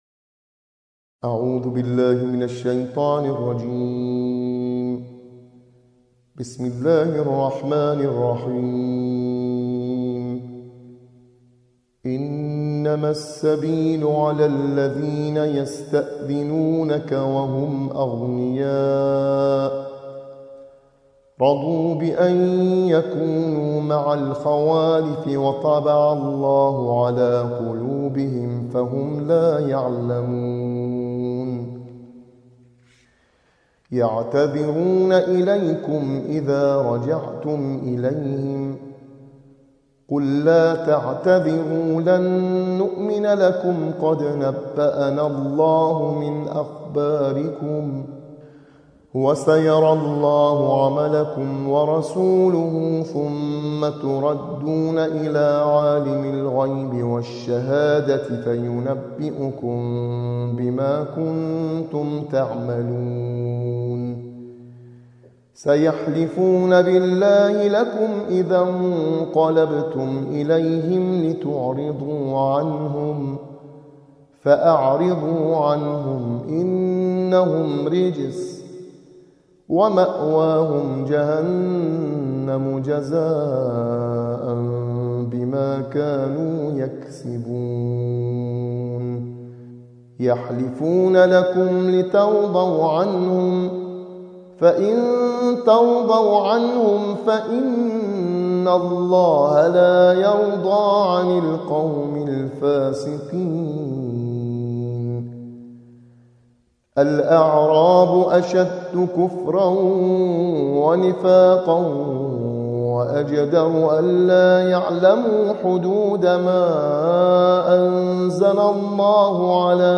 صوت | ترتیل‌خوانی جزء یازدهم قرآن + آموزش نغمات